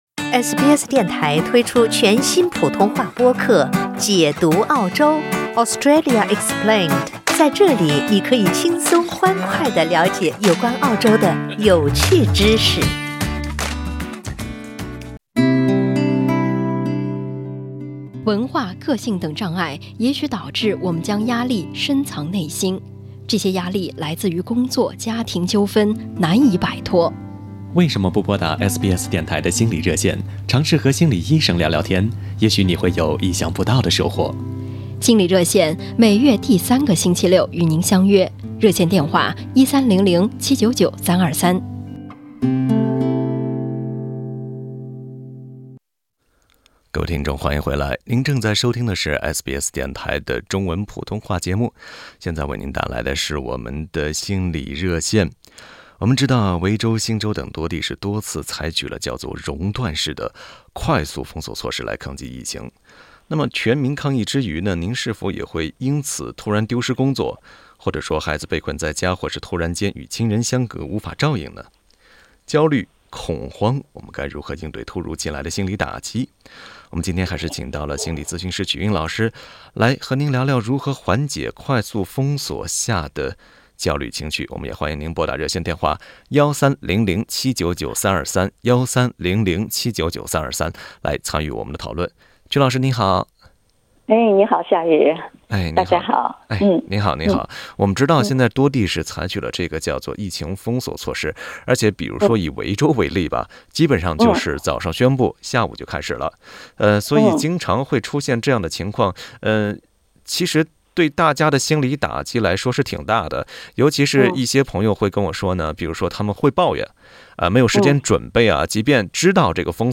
此外，一些聽友還分享了自己在疫情下的經厤及紓解心理壓力的方法。 歡迎您點擊圖片音頻，收聽完整的寀訪。